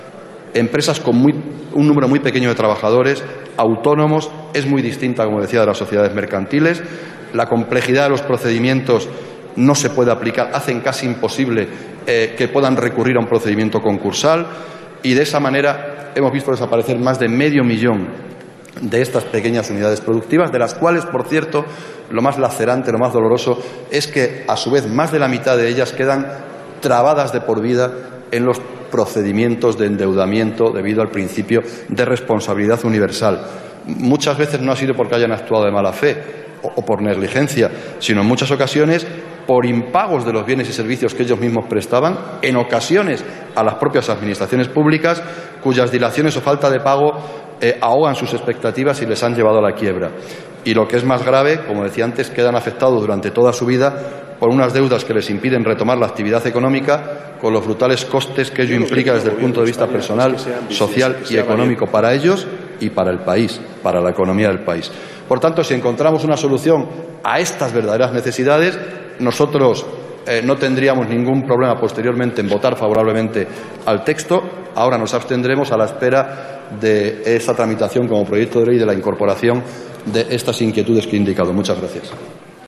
Jesús Caldera en el debate sobre el real decreto de medidas urgentes en materia concursal 25/09/2014